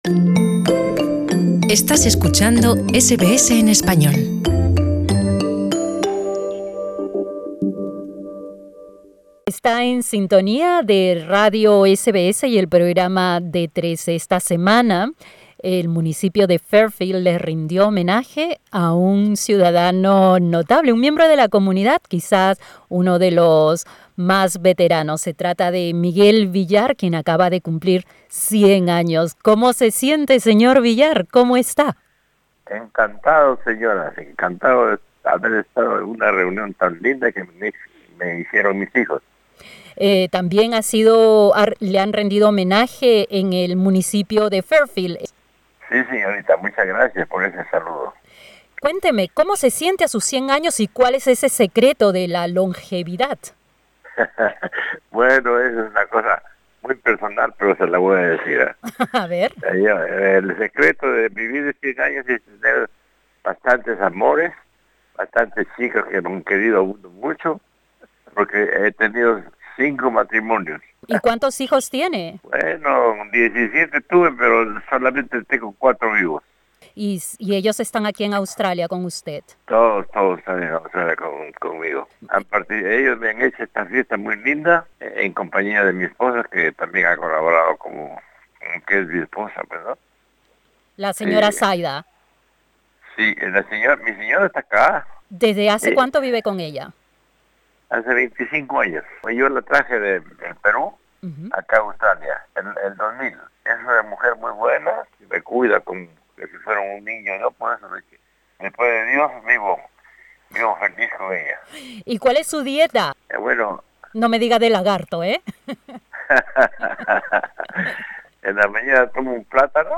Escucha la entrevista Escúchanos en Radio SBS Spanish 24/7 Puedes escucharnos por Radio Digital, a través de nuestro servicio de streaming en vivo aquí en nuestra página web o mediante nuestra app para celulares.